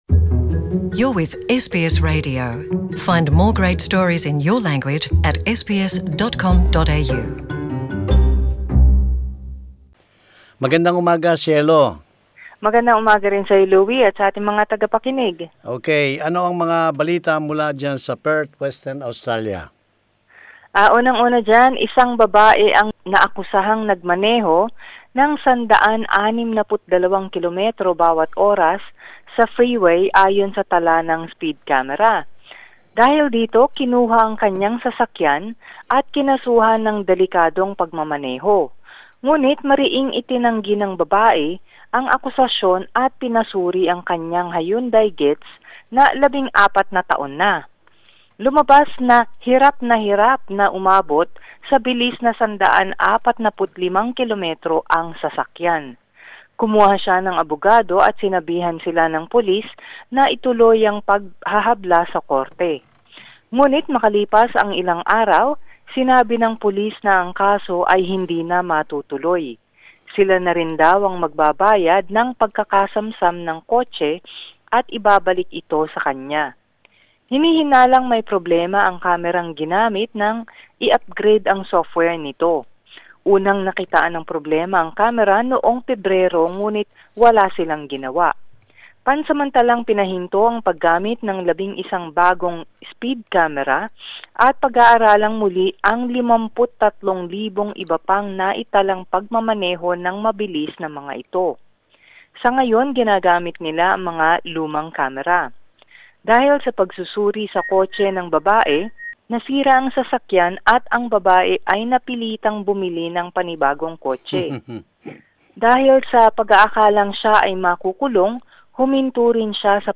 News summary: Woman's car seized in speed camera glitch; 5000 May Day marchers brave rain in WA; Waste collection recycling crisis deepens as Cleanaway and councils locked in stand-off; Country mobile phone and internet bandwidth forces residents online in the middle of the night; Currambine man fined after swampy mosquito-infested pool became health hazard;